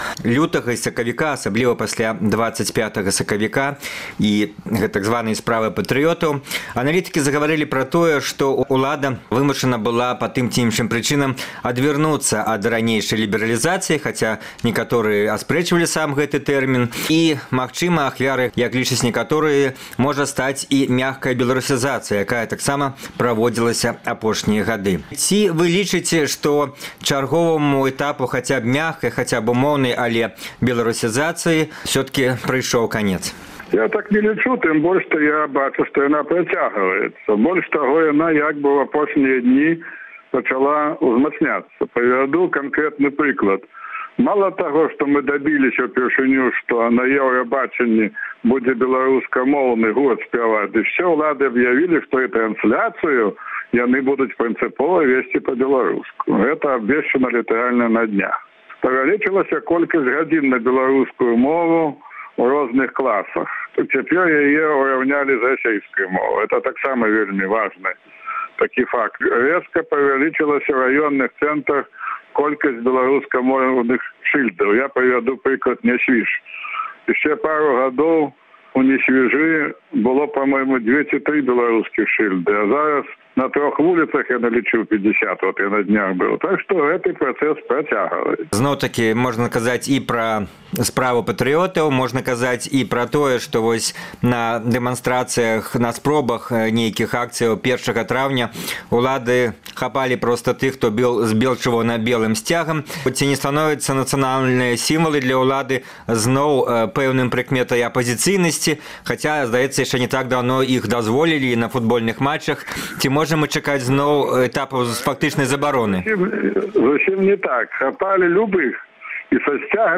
Чарговы герой перадачы “Інтэрвію тыдня” – Старшыня ТБМ Алег Трусаў. Ён адказвае на пытаньне, ці скончылася ў краіне “мяккая беларусізацыя”, тлумачыць, распавядае пра беларускую нацыянальна-патрыятыную групоўку ва ўладзе і абвінавачвае Радыё Свабода ды іншыя незалежныя СМІ ў спрыяньні рэжыму.